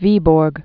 (vēbôrg, -bərk)